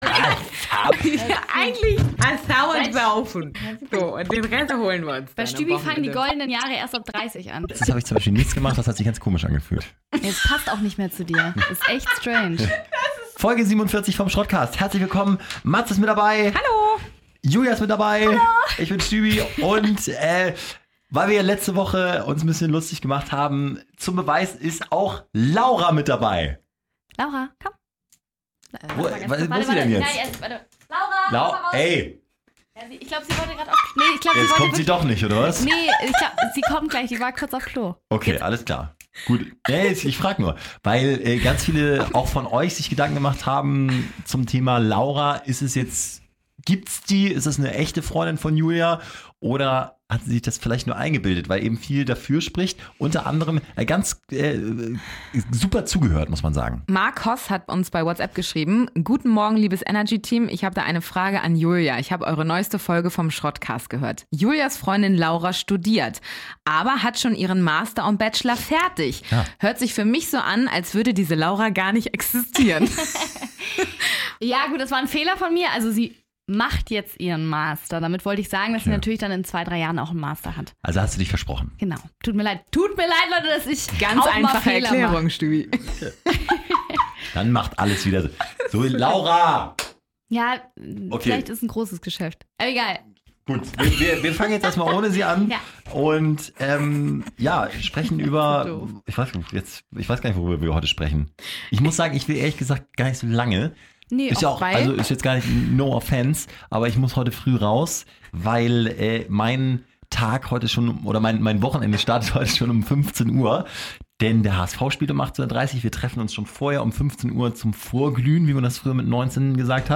Du hörst morgens im Radio eigentlich lieber Gelaber als Musik?
Comedy , Nachrichten